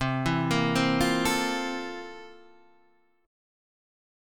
C 13th